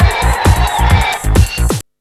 TECHNO125BPM 15.wav